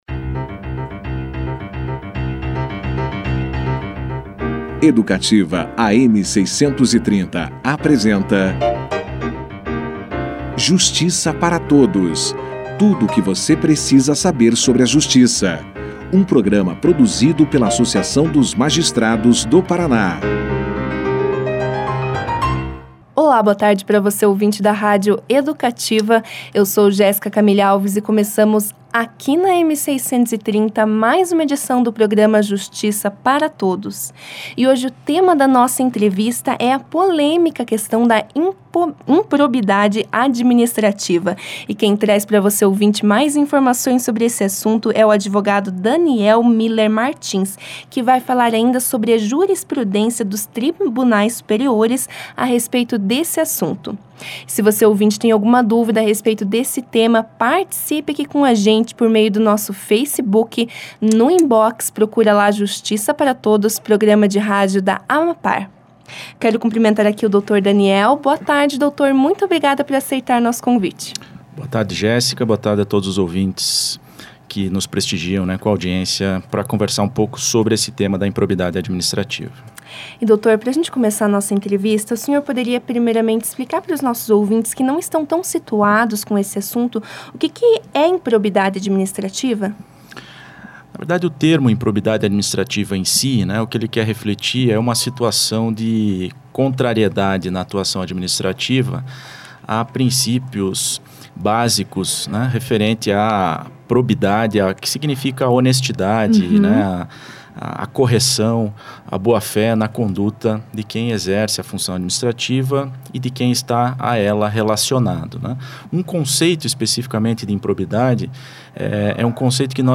O advogado, já no início da entrevista, explicou o conceito de improbidade administrativa.